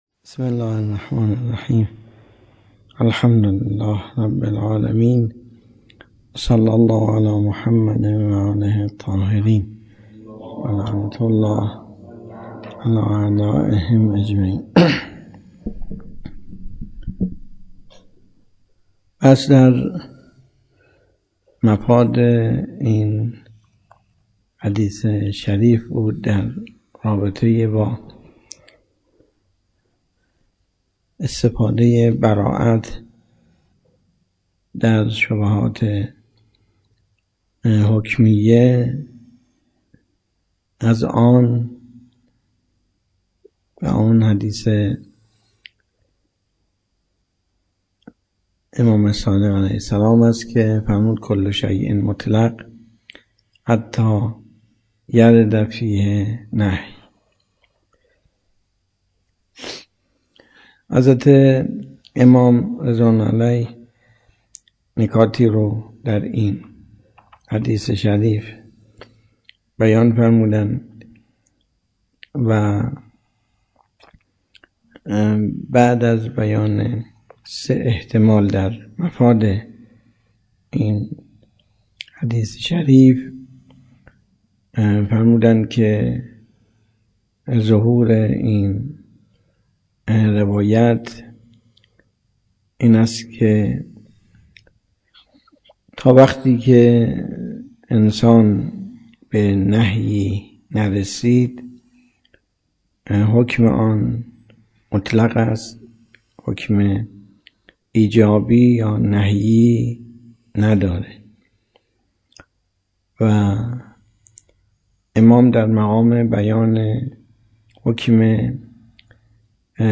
جلسه ۴۶ خارج اصول ۲۵ دی ۱۴۰۲